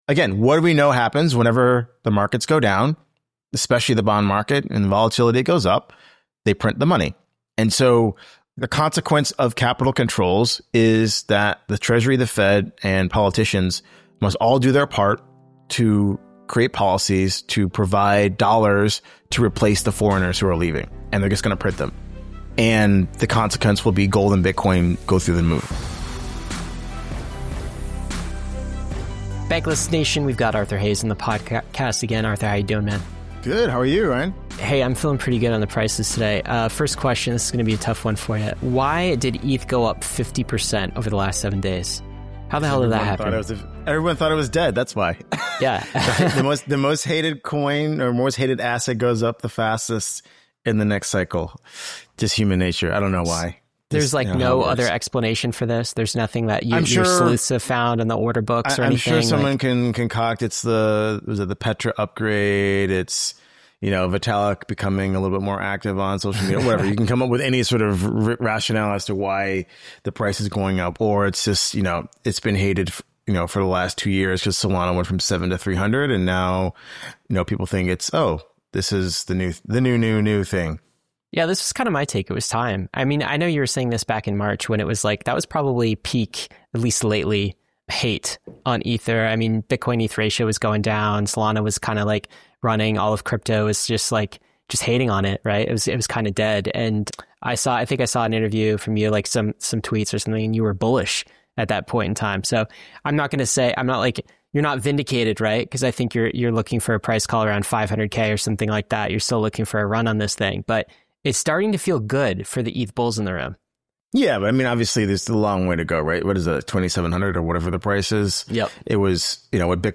Arthur Hayes returns to Bankless for a wide-ranging macro and crypto conversation.